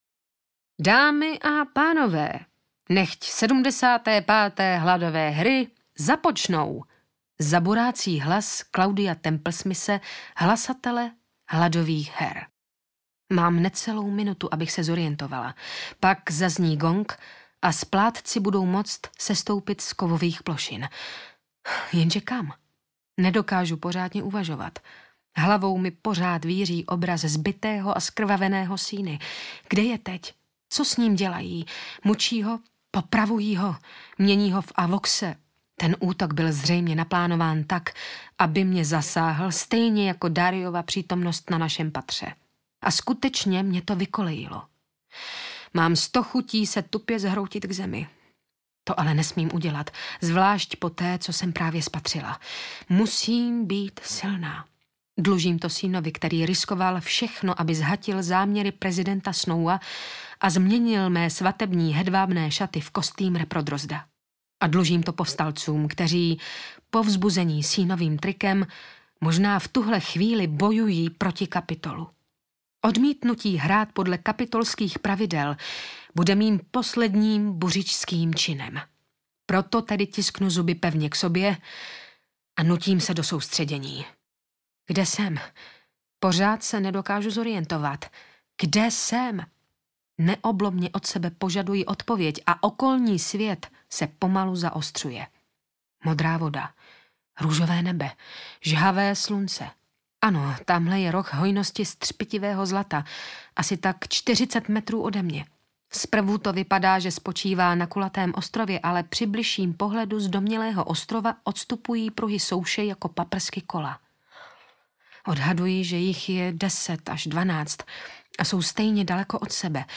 Hunger Games 2 - Vražedná pomsta audiokniha
Ukázka z knihy